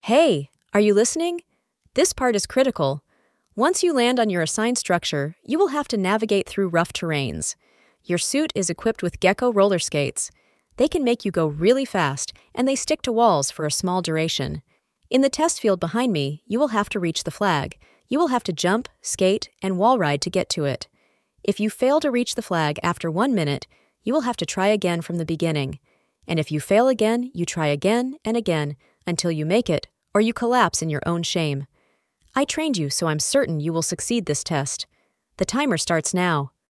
VL_TrainingInstructor_0001.wav